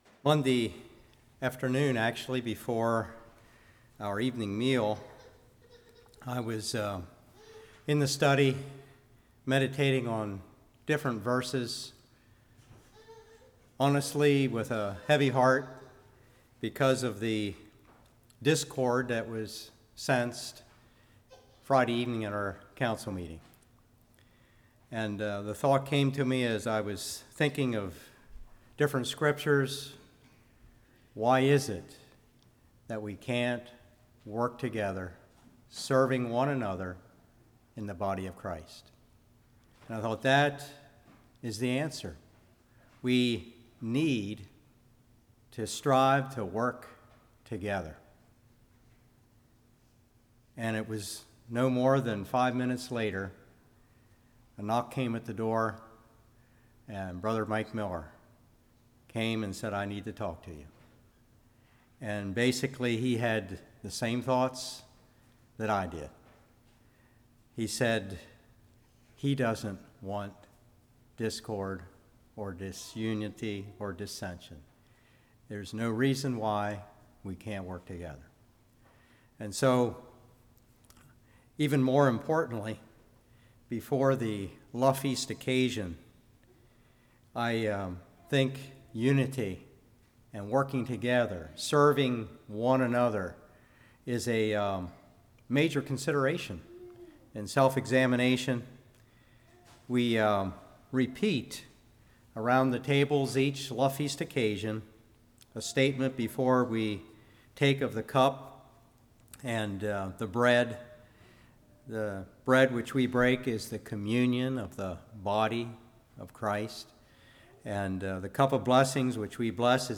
Ephesians 4:1-16 Service Type: Evening Basis of Unity Defined Powerful witness to world « Are You A Citizen of Heaven?